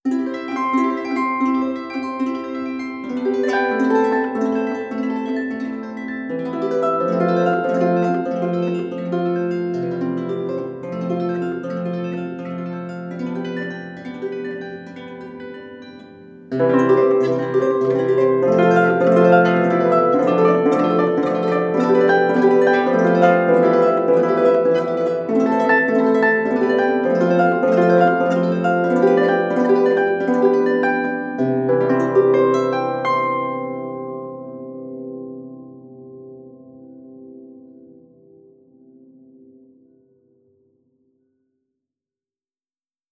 • titan natural harp arpeggio.wav
titan_natural_harp_arpeggio_JTV.wav